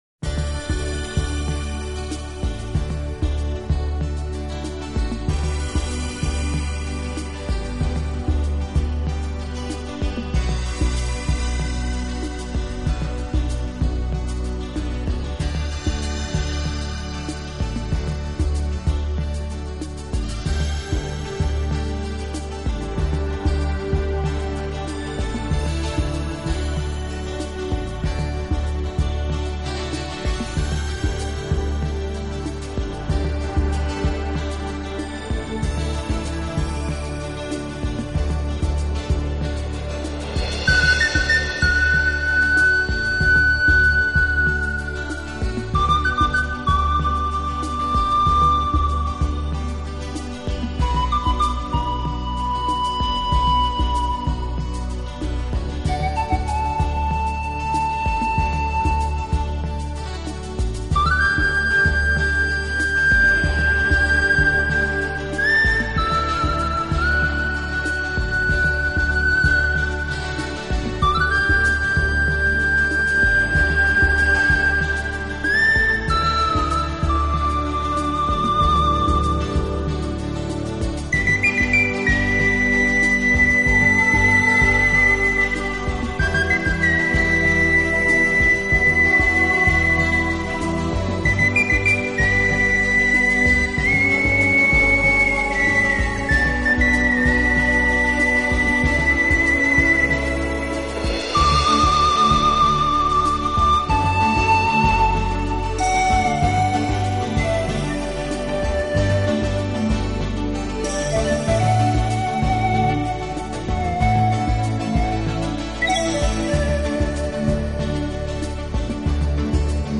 自然一尘不染的精华，仿佛让你远离凡尘嚣暄，置身于世外桃园，尽情享受这天簌之音……